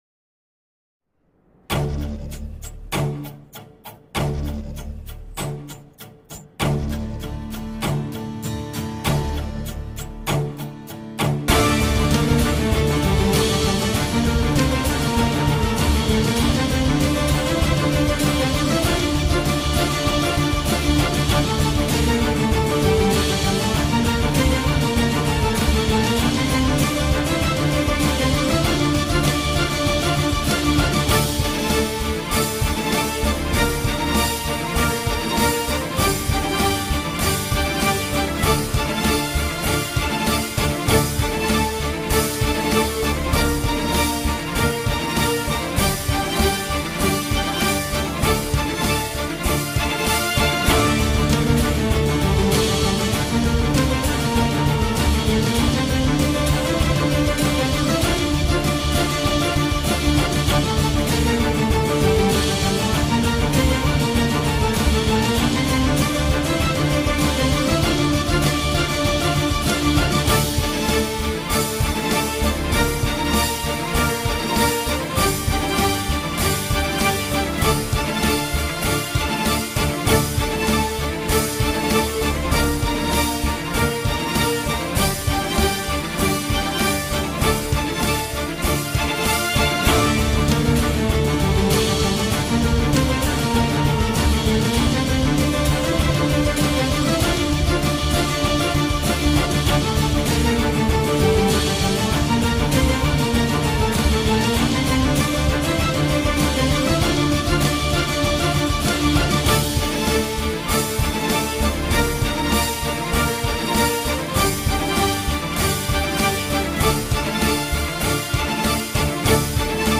tema dizi müziği, heyecan gerilim aksiyon fon müziği.